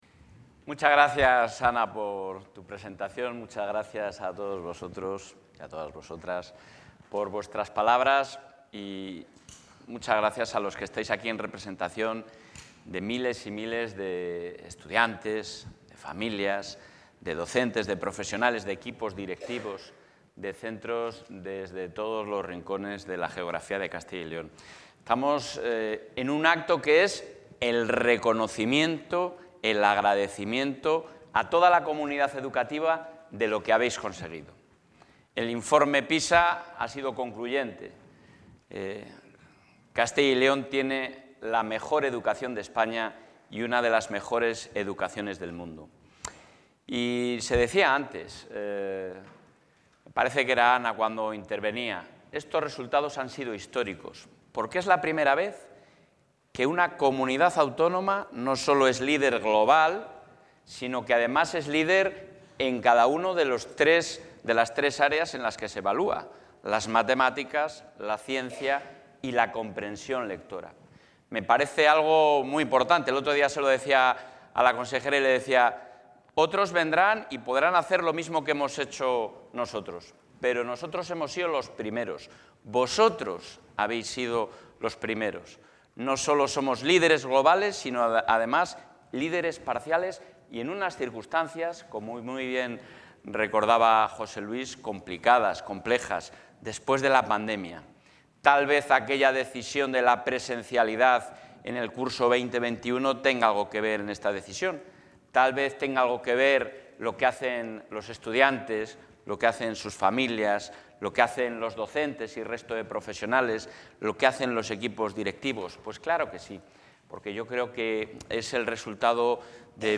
Intervención del presidente de la Junta.
El presidente del Ejecutivo autonómico, Alfonso Fernández Mañueco, ha reconocido el esfuerzo, trabajo y resultados de la comunidad educativa de Castilla y León, en un acto en el que han participado profesores, alumnos y padres de diferentes centros de la Comunidad.